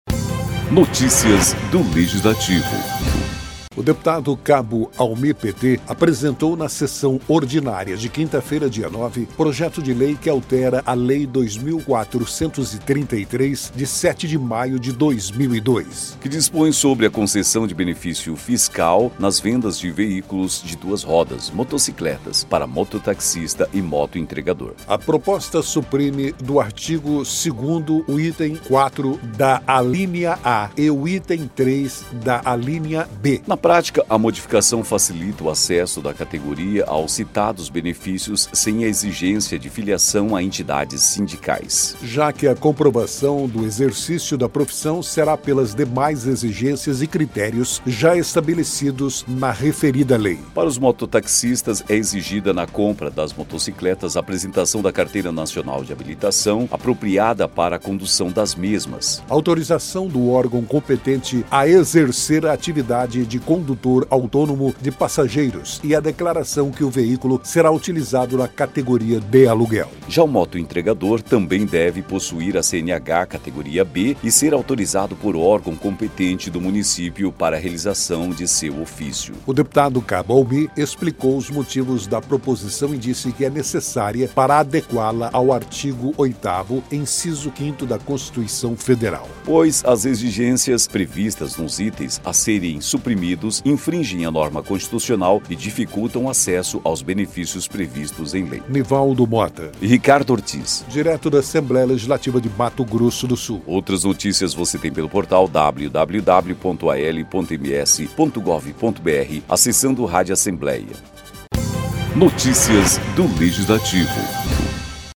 Recuperação de Estradas – O deputado também usou a tribuna para cobrar o recapeamento da BR-262, no trecho entre Campo Grande e Água Clara.